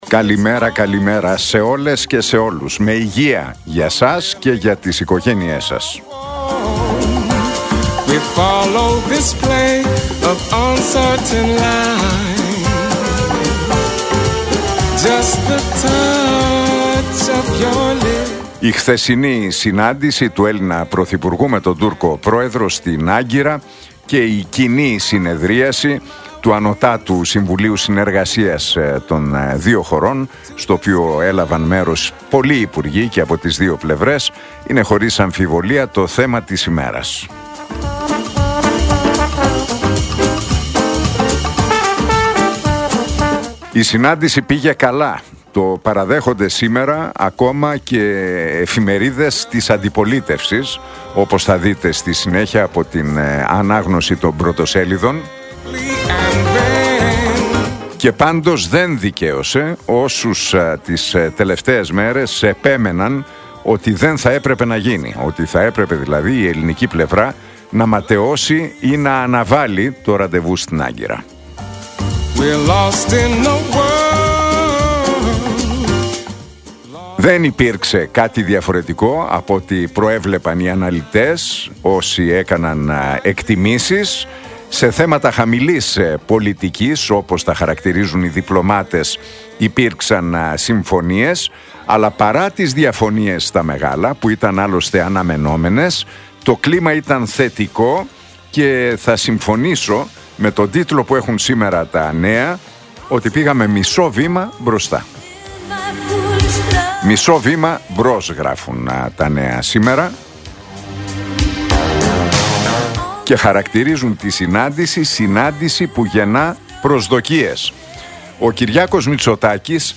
Ακούστε το σχόλιο του Νίκου Χατζηνικολάου στον ραδιοφωνικό σταθμό Realfm 97,8, την Πέμπτη 12 Φεβρουαρίου 2026.